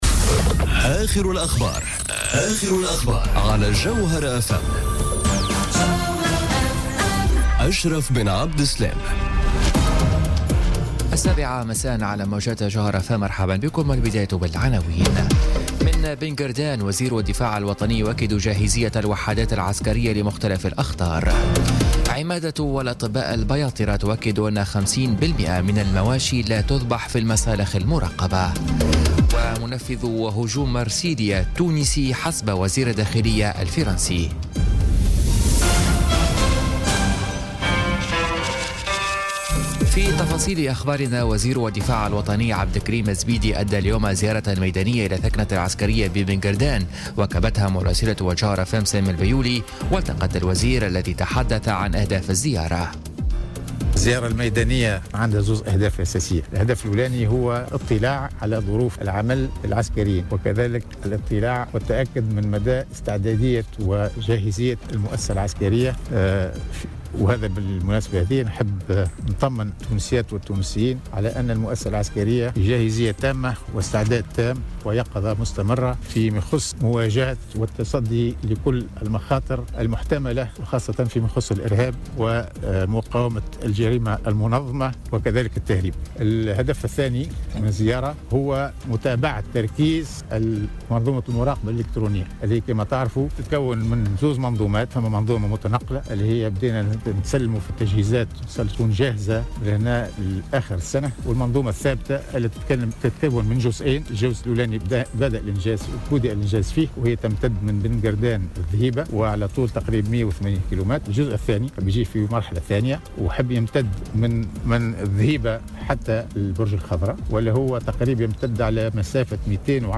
نشرة أخبار السابعة مساء ليوم الثلاثاء 3 أكتوبر 2017